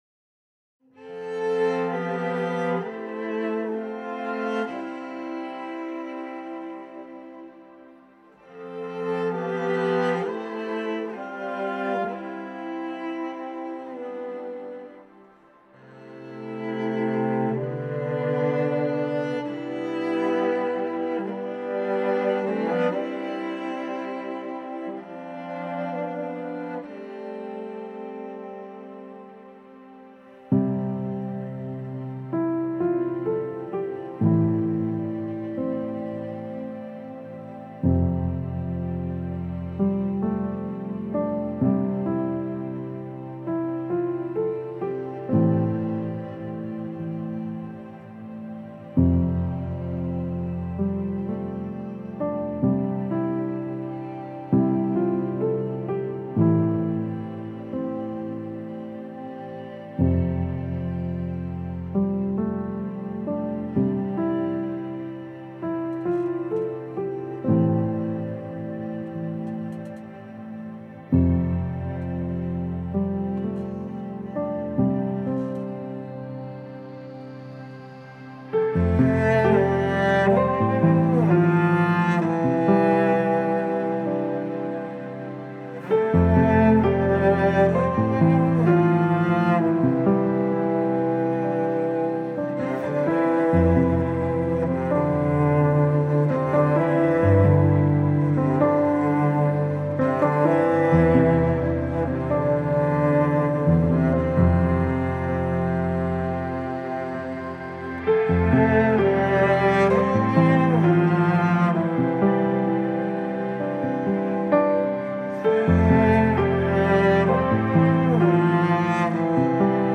آرامش بخش , الهام‌بخش , عصر جدید , موسیقی بی کلام
موسیقی بی کلام آرامبخش موسیقی بی کلام نیو ایج